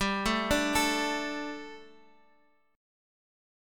D5/G chord